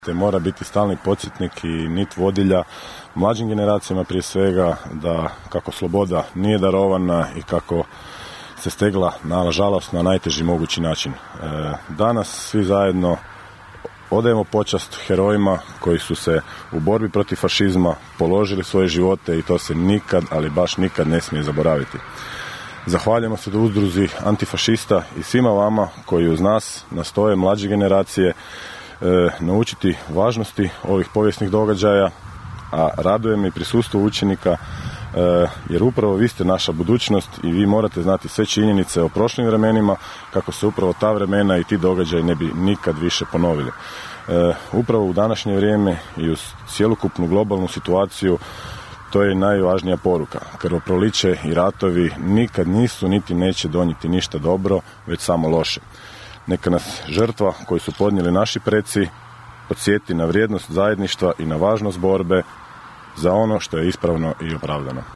U prigodnom obraćanju, nakon polaganja vijenca i paljenja svijeća, zamjenik gradonačelnika Labina Goran Vlačić istaknuo je kako je ponosan što su se danas okupili na lokaciji koja ima posebnu važnost za naš kraj: (